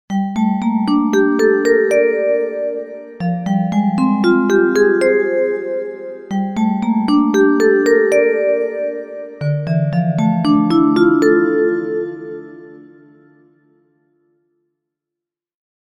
Genres: Sound Logo